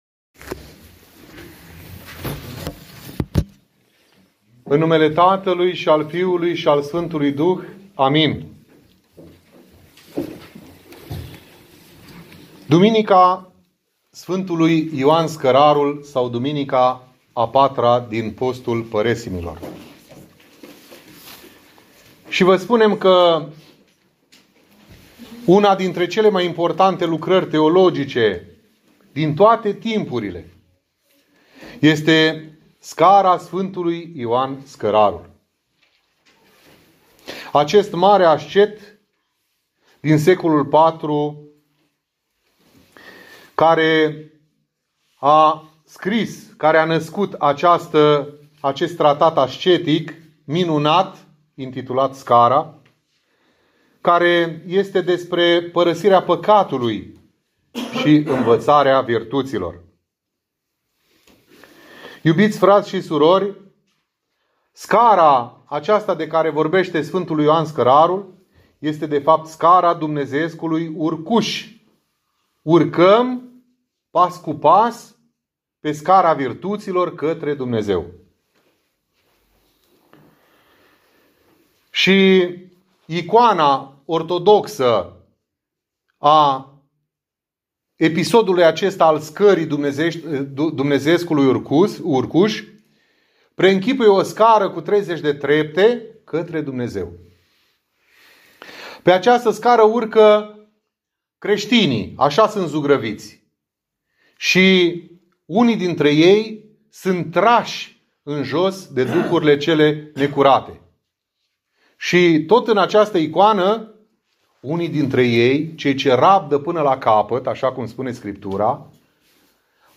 Predica